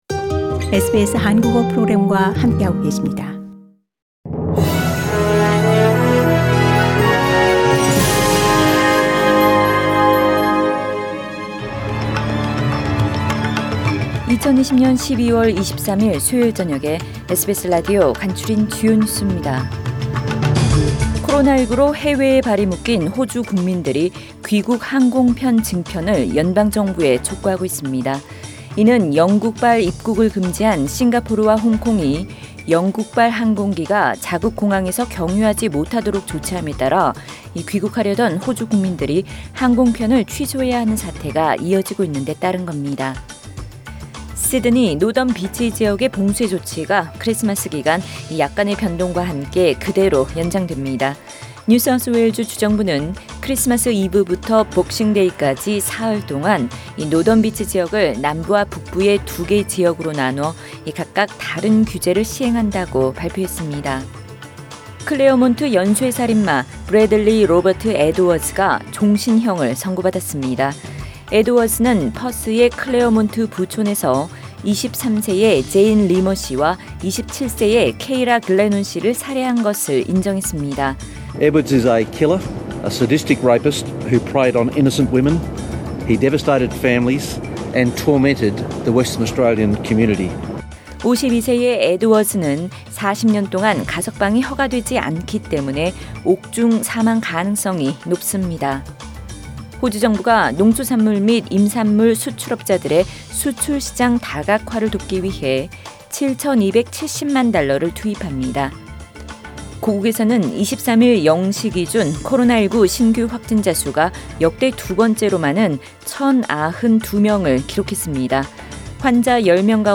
SBS News Outlines…2020년 12월 23일 저녁 주요 뉴스